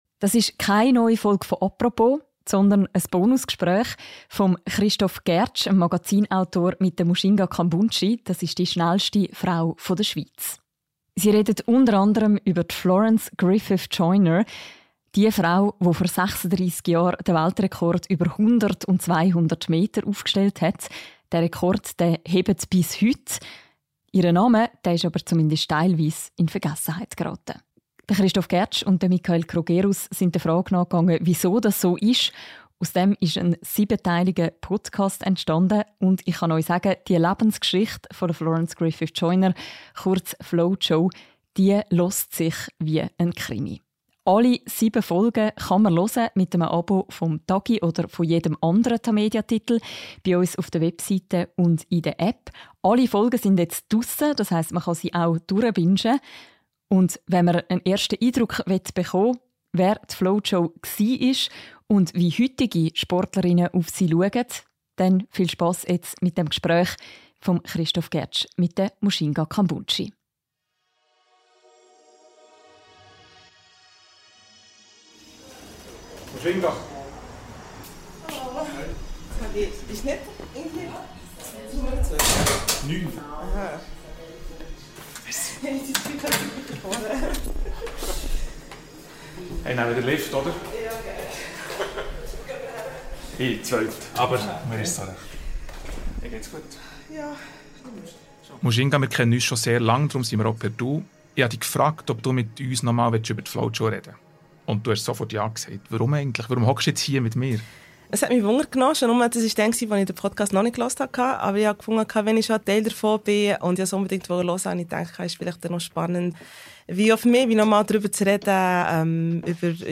Und was hat Mujinga Kambundji im Podcast über Flo-Jo erfahren, das sie noch nicht wusste? Zeit für ein Gespräch in Bern, kurz vor Kambundjis Abreise nach Rom, wo sie im Juni Europameisterin über 200 Meter wurde und diesen Freitag nun am Diamond-League-Meeting startet.